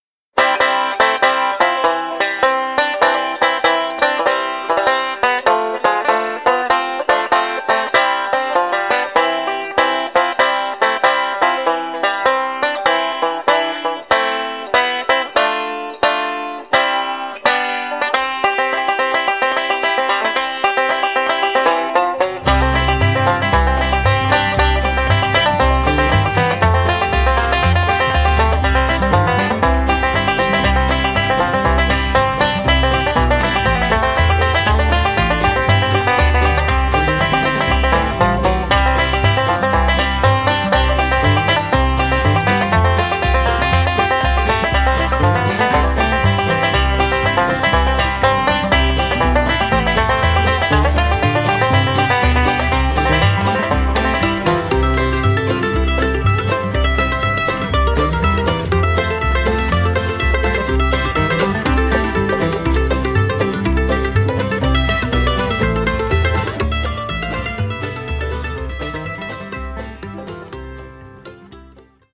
Listen to Eddie Adcock perform "Battle Hymn Of The Republic" on the 5-string banjo (mp3)